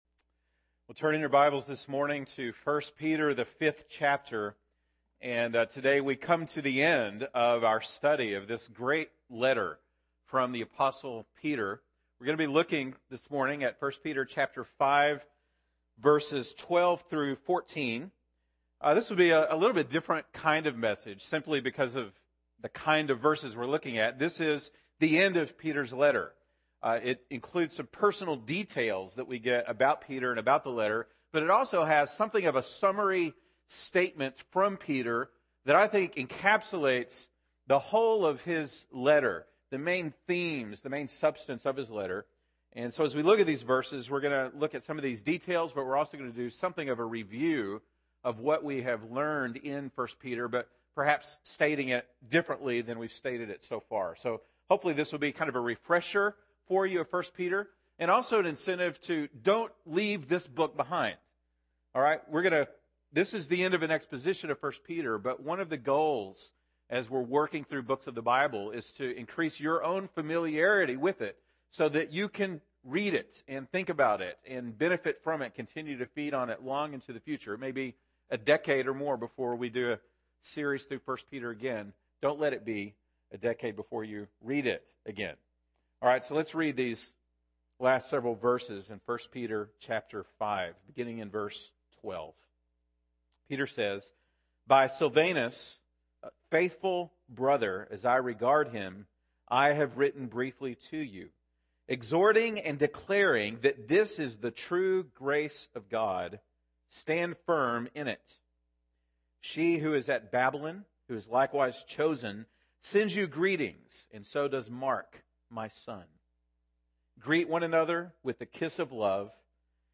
November 22, 2015 (Sunday Morning)